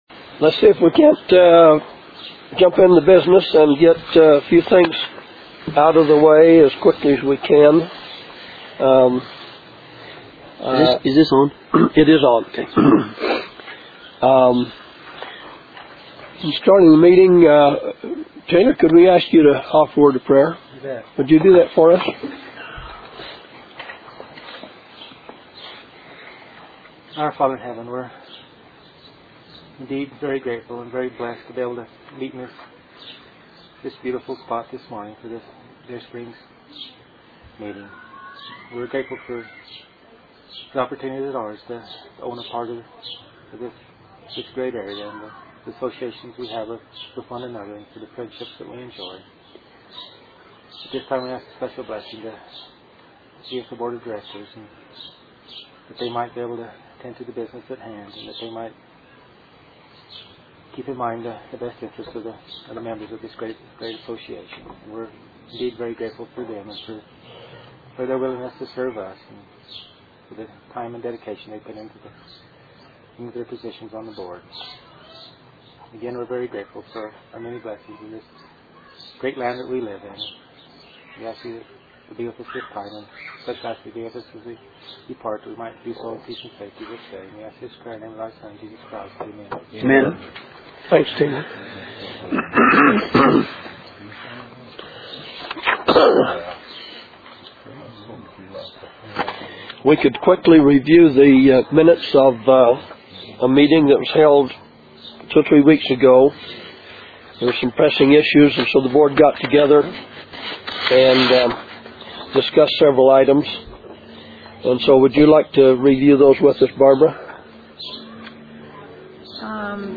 Deer Springs Ranch Owners Association Board Meeting June 25, 2005 Click a hyperlinked topic to hear the audio for it.
NOTE: Partial recording only.